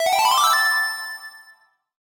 sound3_gameover.ogg